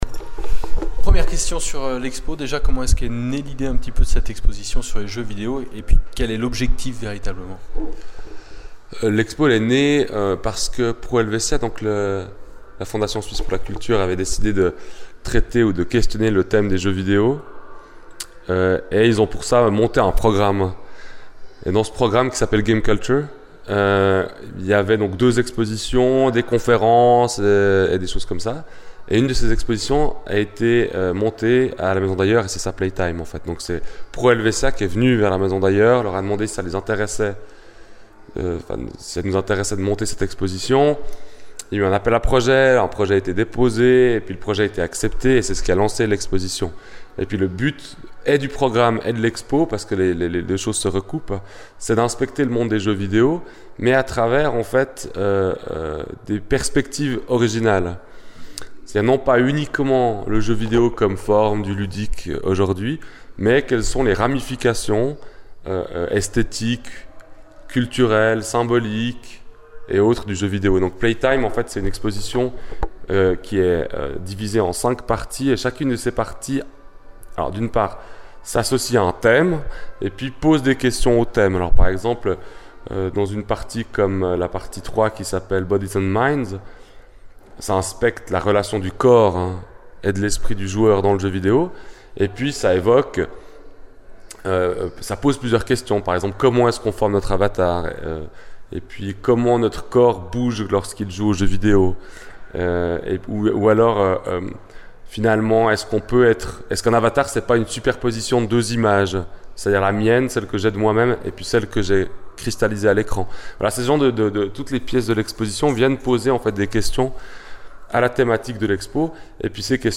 Playtime : Interview